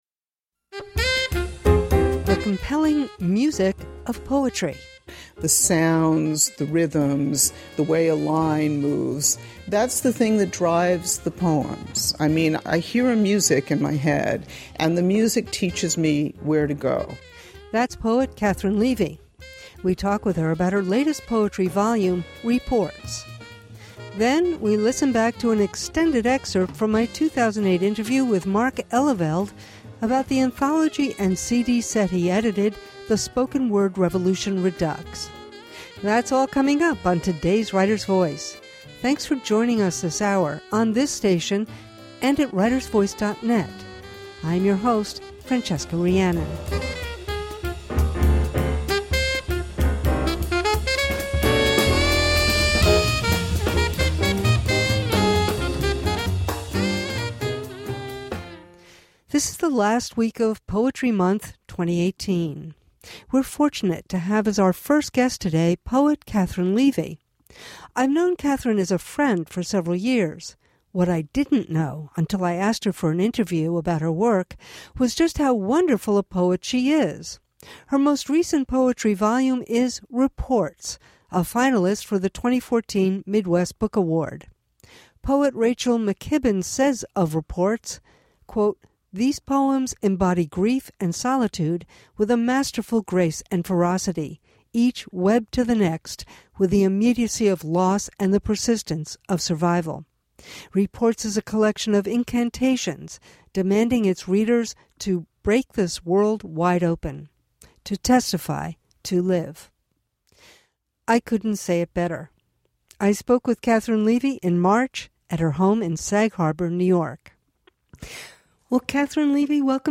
This is the first week of April and April is Poetry Month. So we are so pleased to feature two conversations with poets who use their genre as a vehicle for historical witness and spiritual transformation.